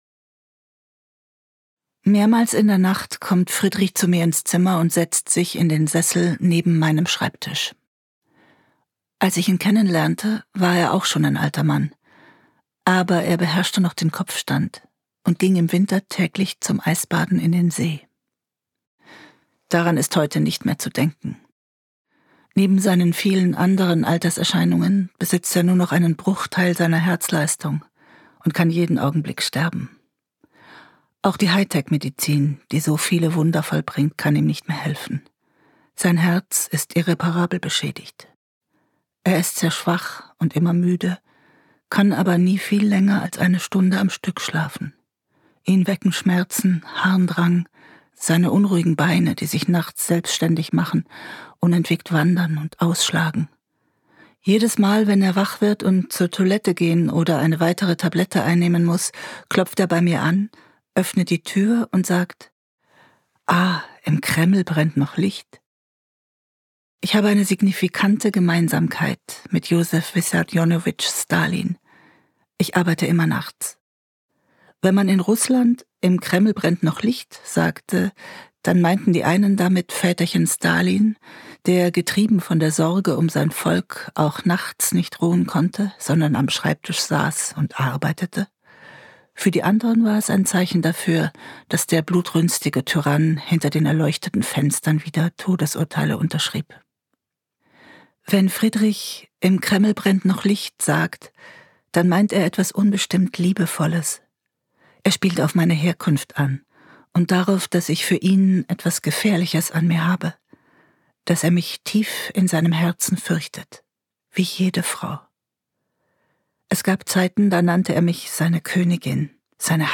Die späten Tage Gelesen von: Martina Gedeck